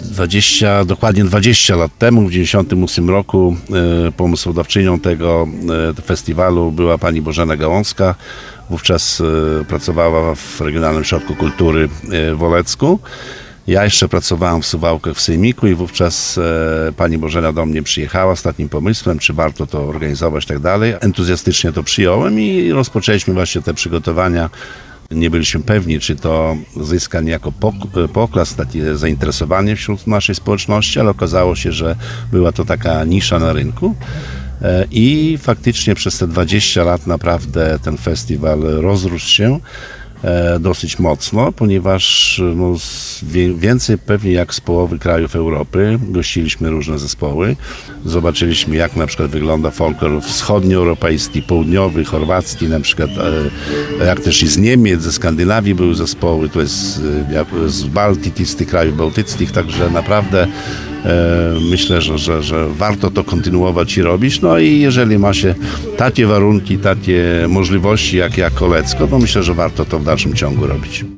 – Tegoroczny festiwal jest wyjątkowy. W tym roku mija bowiem 20 lat od pierwszego spotkania miłośników folkloru w Olecku – powiedział Radiu 5 Wacław Olszewski, burmistrz Olecka.